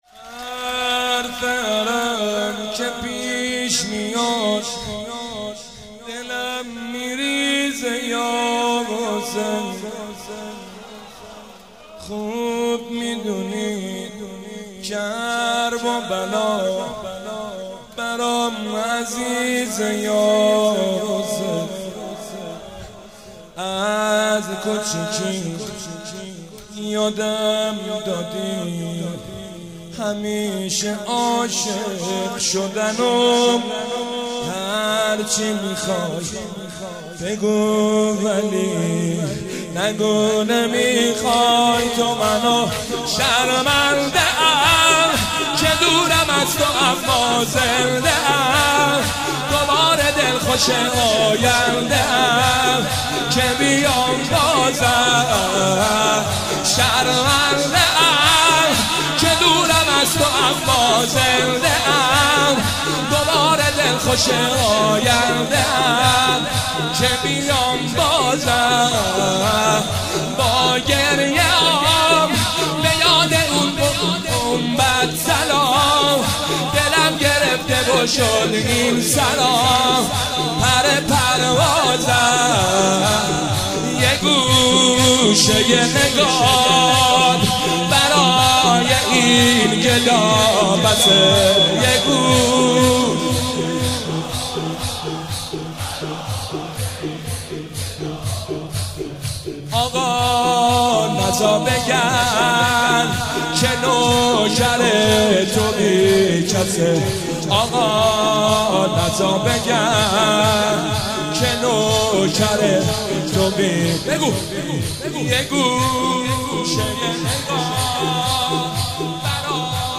گلچین مداحی شب اول محرم
شور (۲)